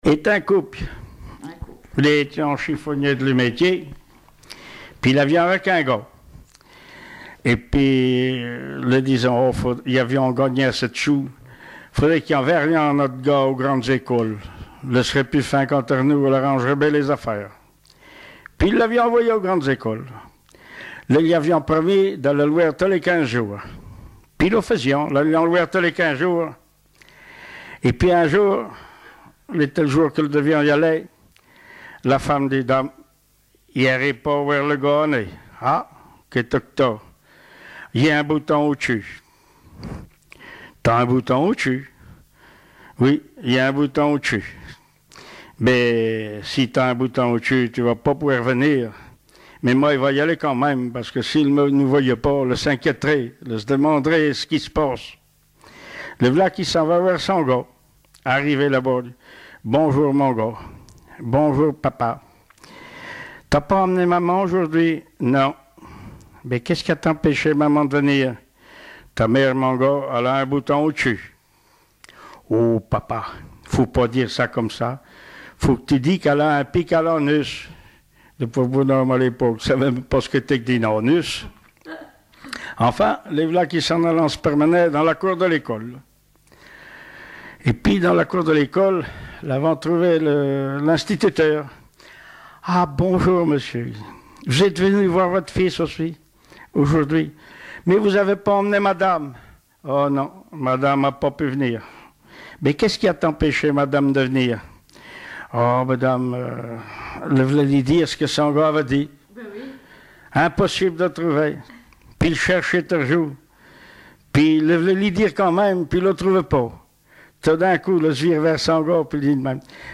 Mémoires et Patrimoines vivants - RaddO est une base de données d'archives iconographiques et sonores.
Langue Patois local
Genre sketch
Catégorie Récit